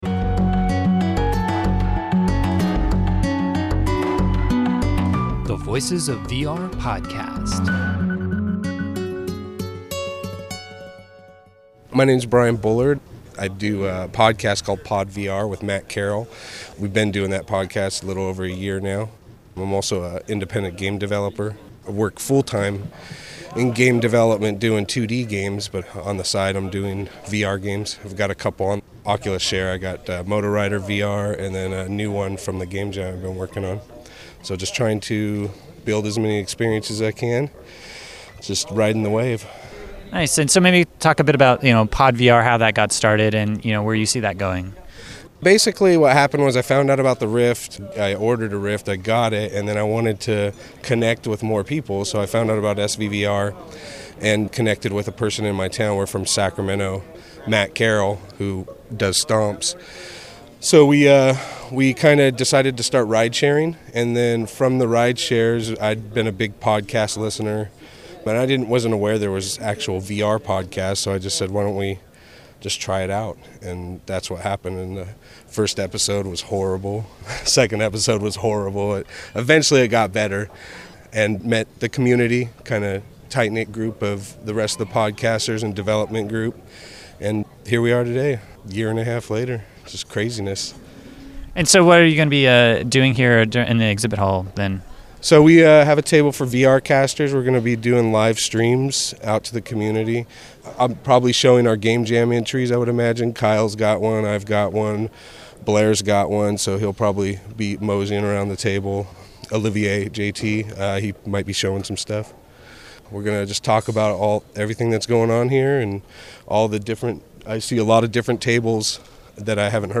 Here’s the other topics that we cover in the interview: